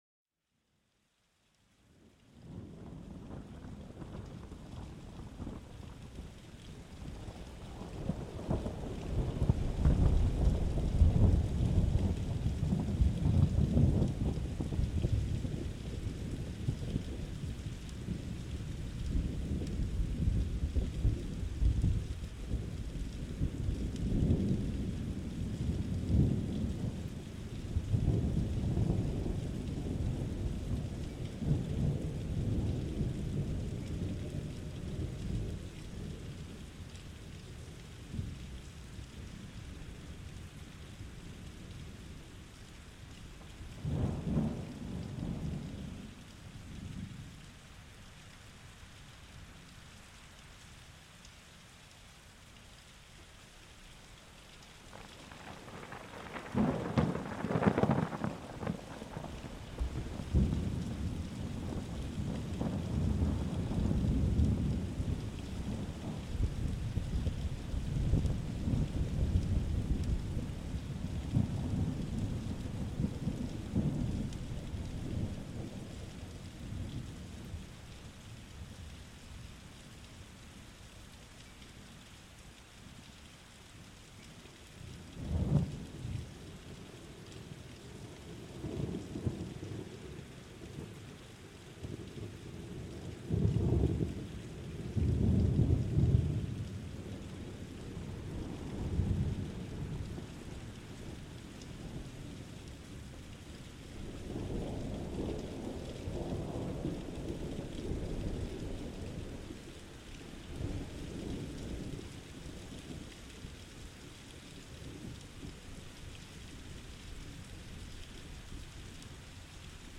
Plongée au cœur d'un gros orage apaisant pour une relaxation profonde